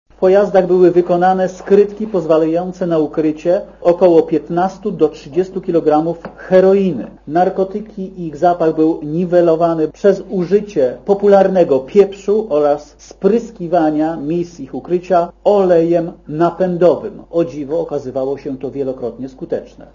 Mówi prokurator